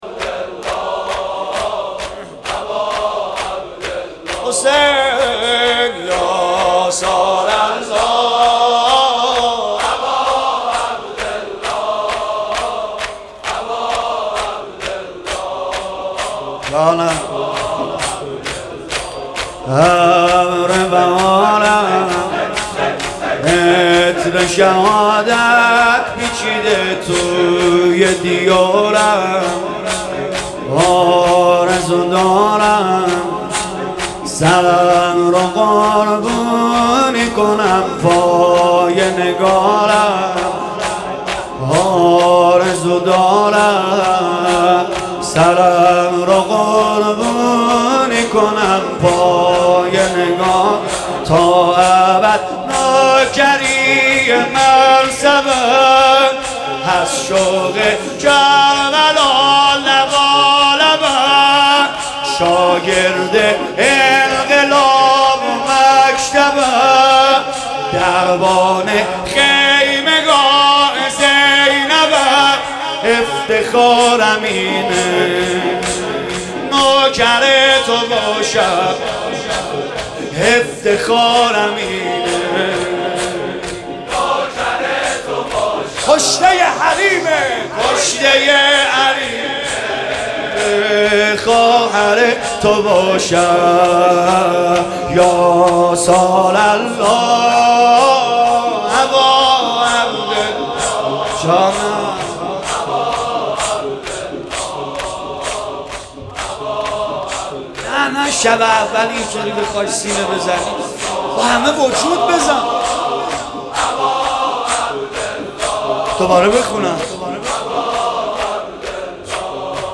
ابر بهارم(شور شهدایی)